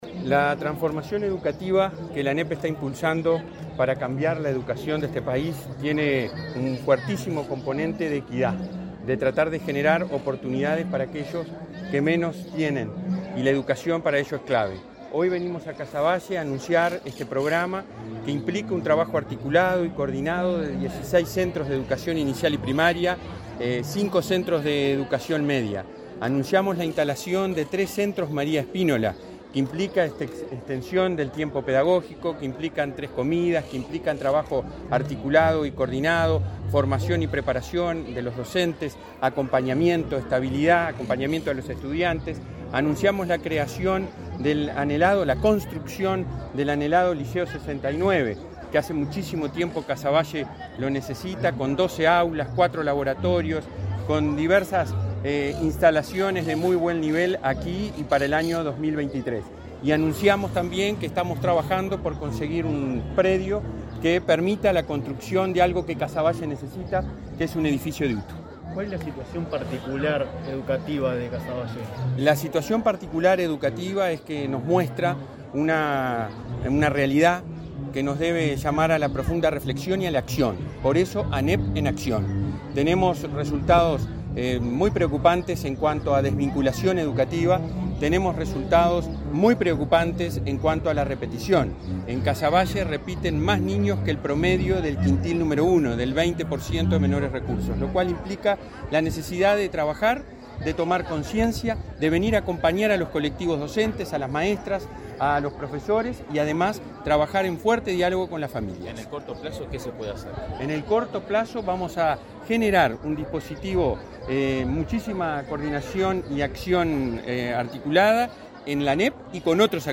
Declaraciones del presidente de ANEP, Robert Silva, a la prensa